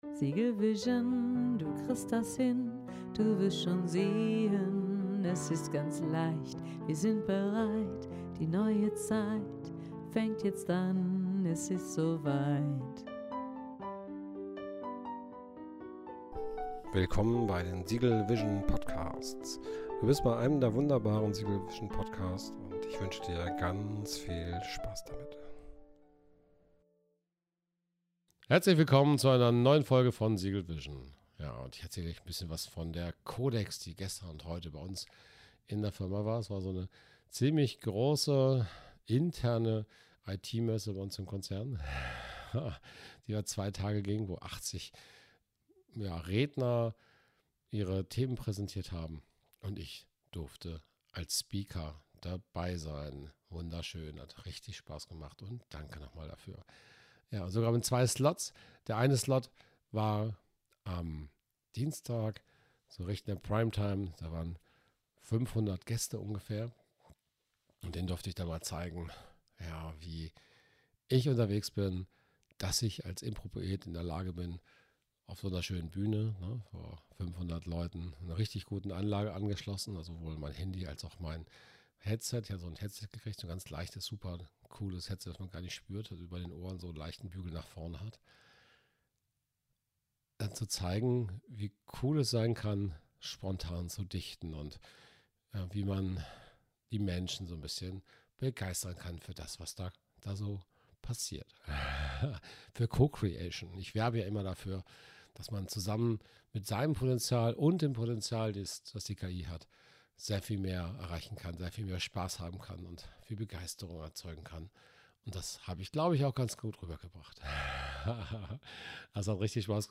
cooler Bühnenauftritt
Jubel und Begeisterung